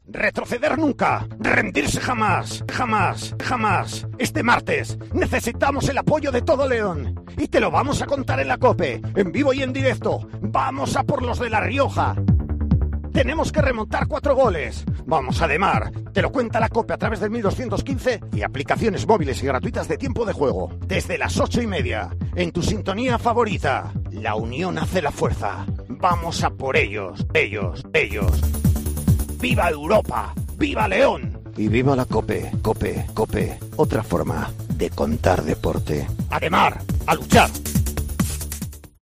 Escucha la cuña promocional del partido Abanca Ademar-Logroño el día 28-09-21 a las 20:45 h en el 1.215 OM